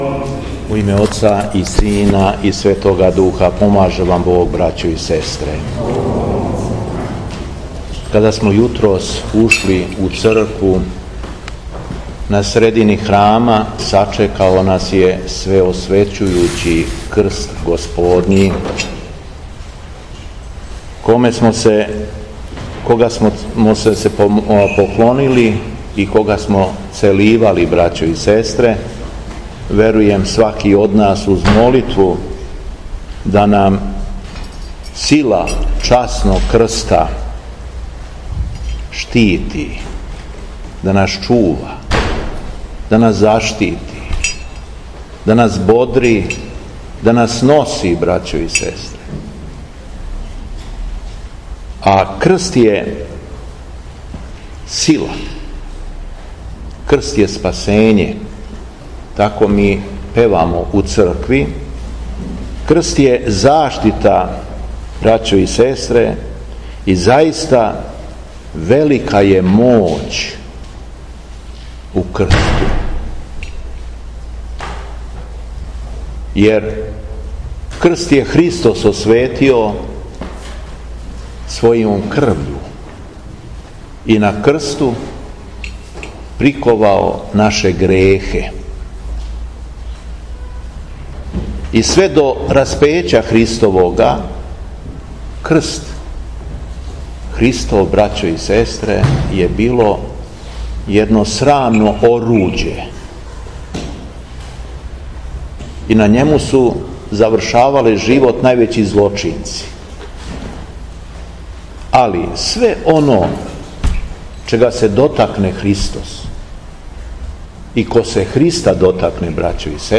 Беседа Његовог Преосвештенства Епископа шумадијског Г. Јована
У први дан Великогоспојинског поста, 14/1. августа, када наша Црква прославља Изношење Часног Крста и Свете мученике Макавеје, епископ шумадијски Господин Јован служио је Свету Архијерејску Литургију поводом храмовне славе посвећене данашњем празнику у Старој цркви у Крагујевцу.